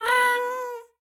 Minecraft Version Minecraft Version 25w18a Latest Release | Latest Snapshot 25w18a / assets / minecraft / sounds / mob / happy_ghast / ambient8.ogg Compare With Compare With Latest Release | Latest Snapshot